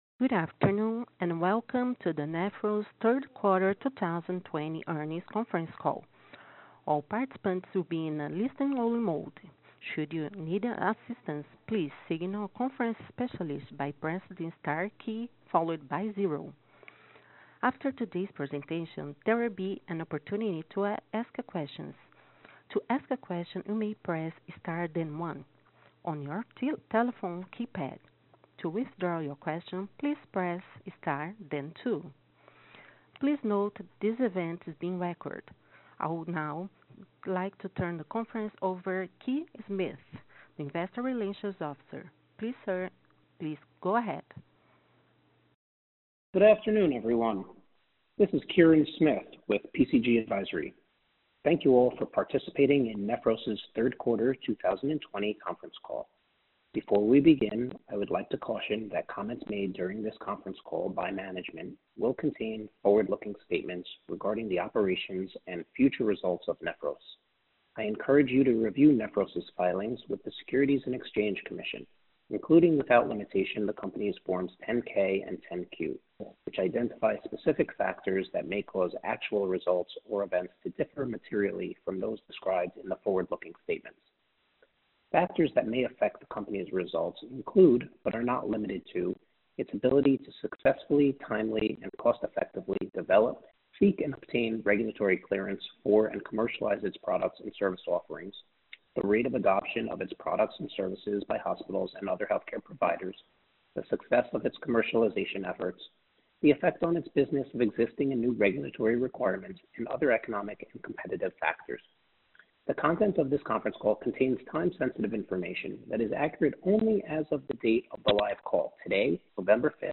Q2 2021 Conference Call Replay